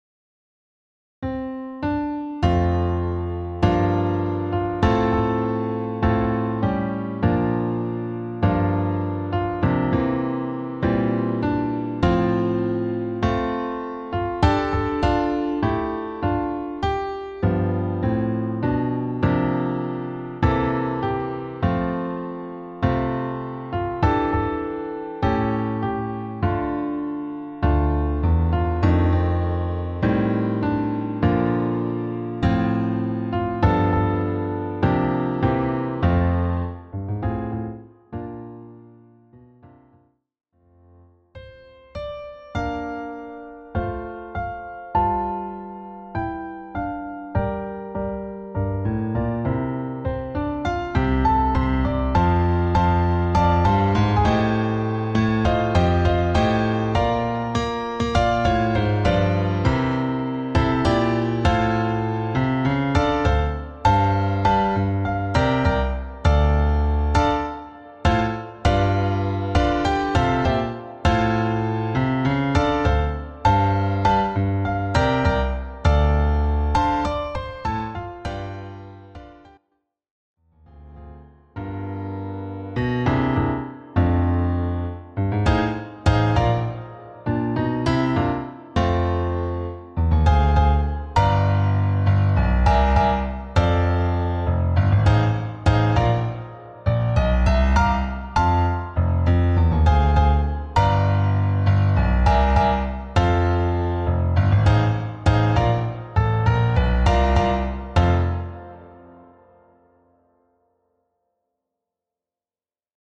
Instrumentierung: Klavier solo